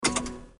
engineoff.mp3